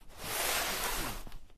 wall_slide.ogg